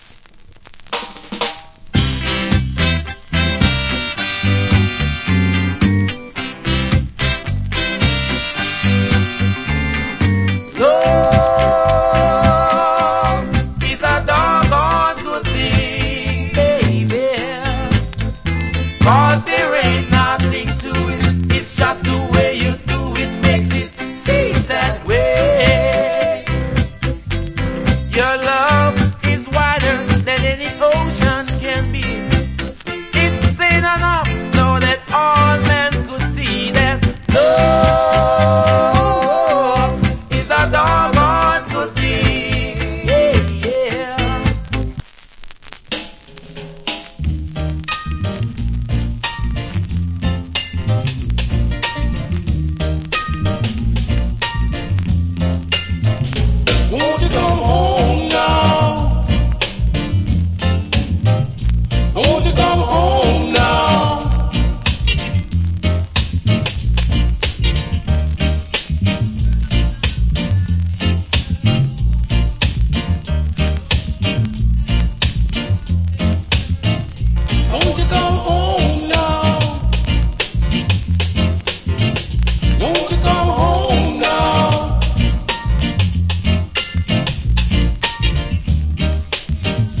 Nice Early Reggae Vocal.